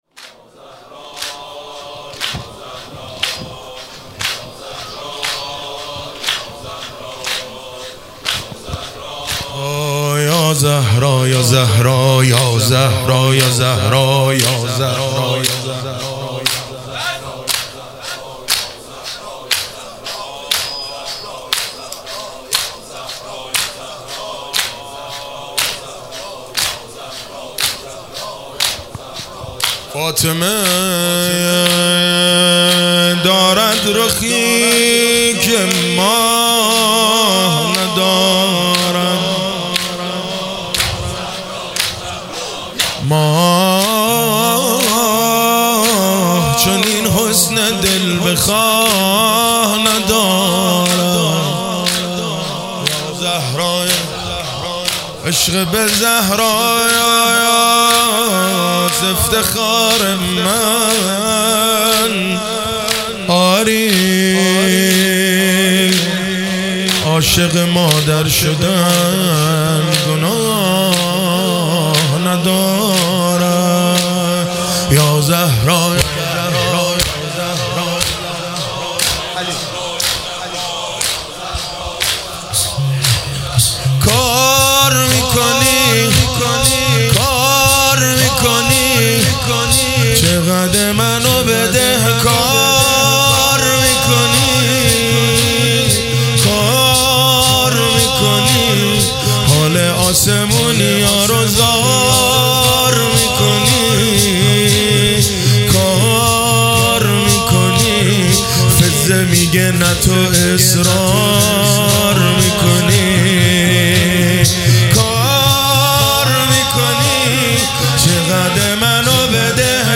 روضه هیئت رایه الرضا علیه السلام